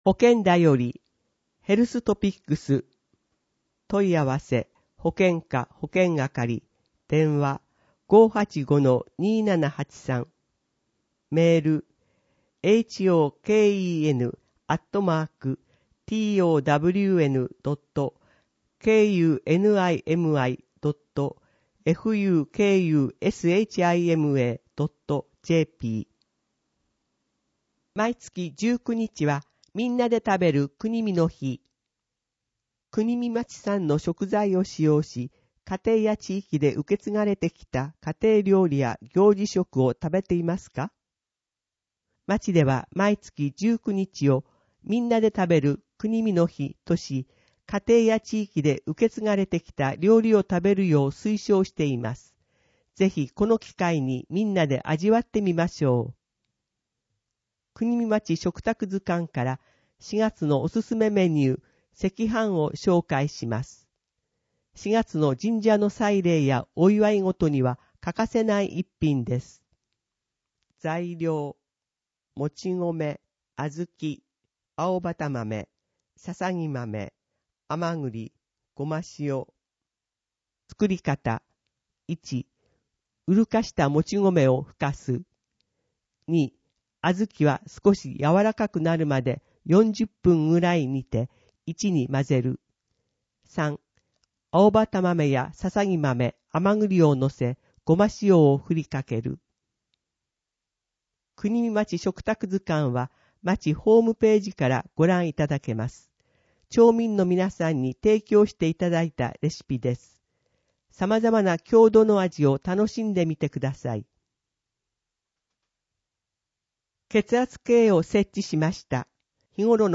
＜外部リンク＞ 声の広報 声の広報(1) [その他のファイル／14MB] 声の広報(2) [その他のファイル／13.69MB]